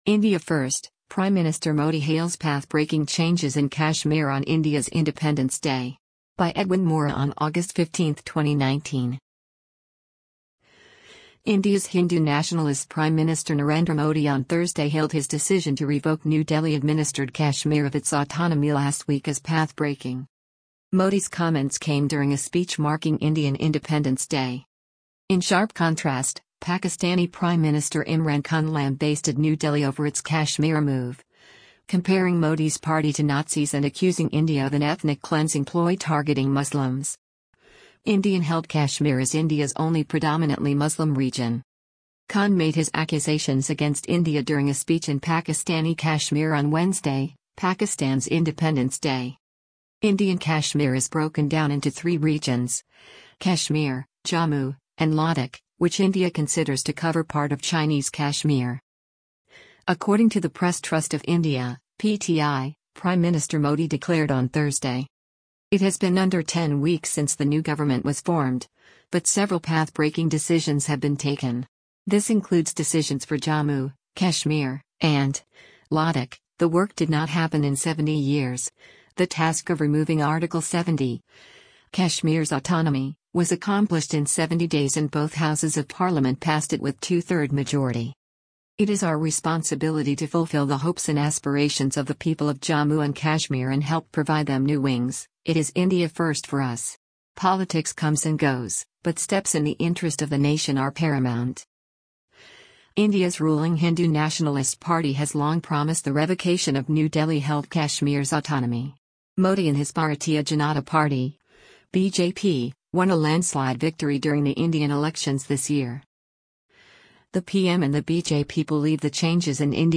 India's Prime Minister Narendra Modi delivers a speech to the nation during a ceremony to
Modi’s comments came during a speech marking Indian Independence Day.